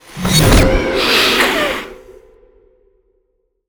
dark_magic_conjure_blast_3.wav